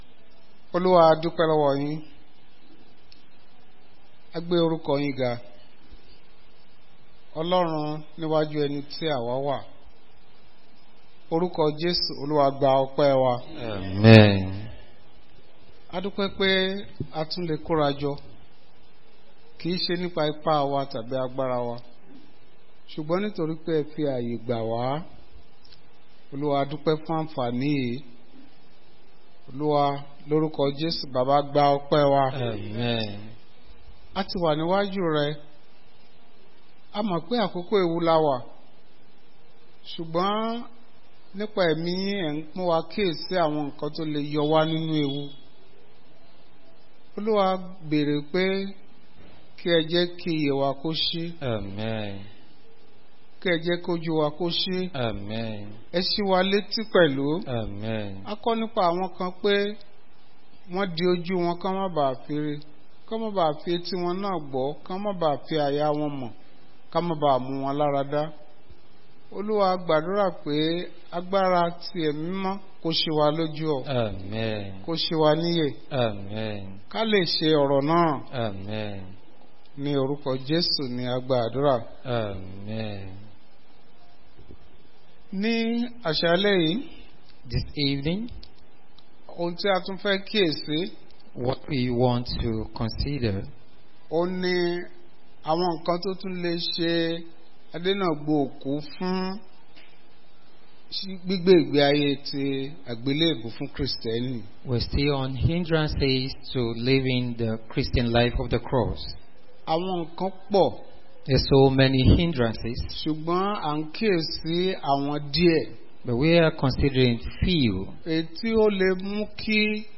Bible Class Passage: Luke 9:57-61